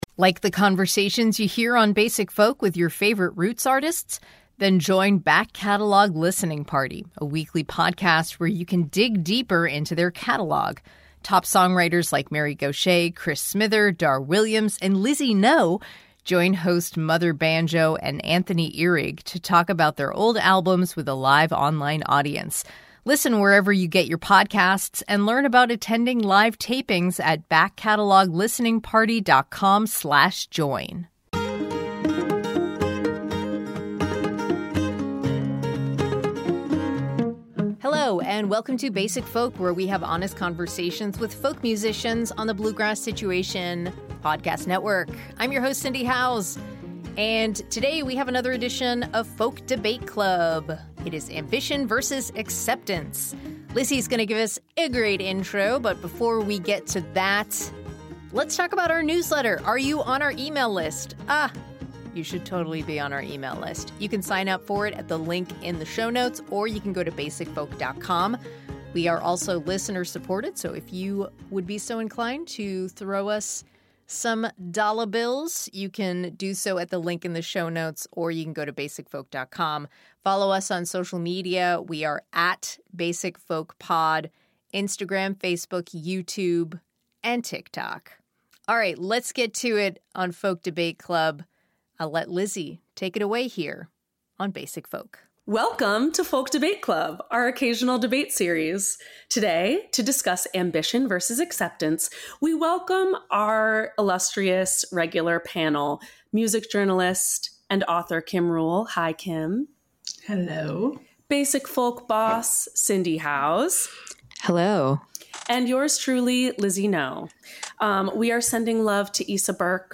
Welcome to another edition of Folk Debate Club, our occasional debate series!